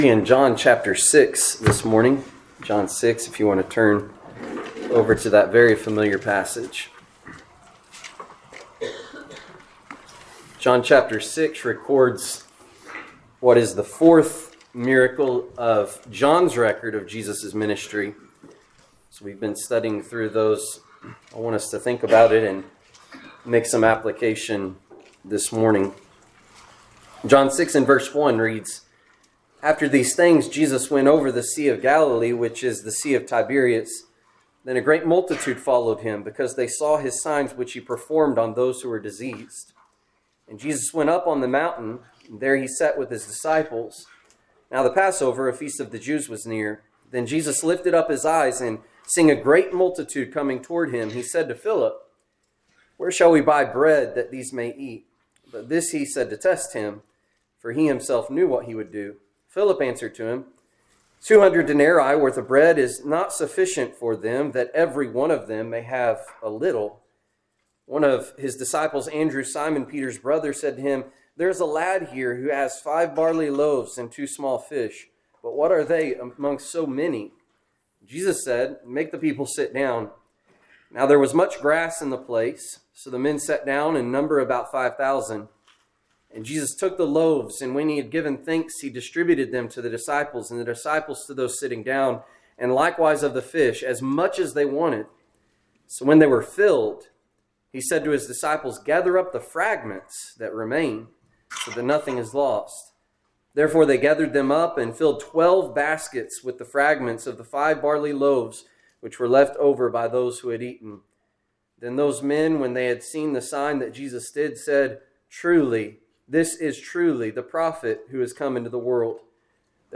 Seasonal Preaching Sermon Podcast